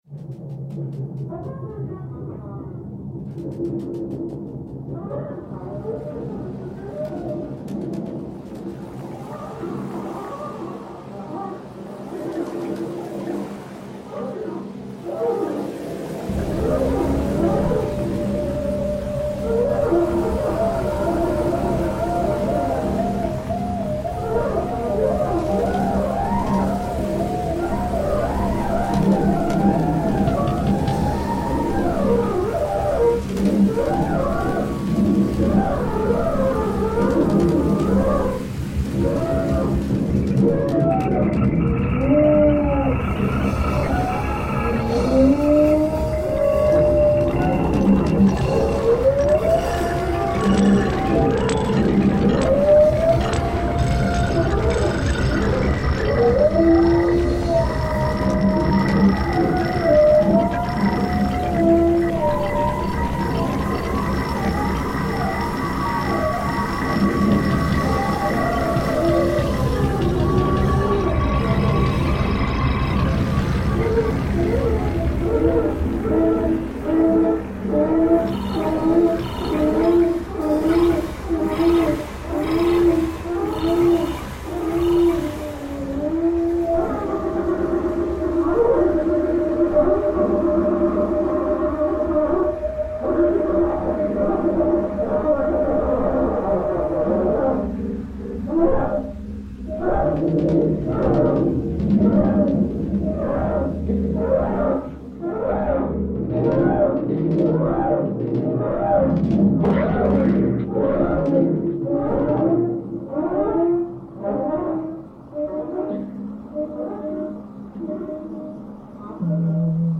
Instrumental.
Experimental.
Improvised.
Mostly Pretty Sounds.
Acoustic and electric sources.
Drums, Horns, Synths, Guitars, Muchos Effectos.